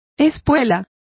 Complete with pronunciation of the translation of spur.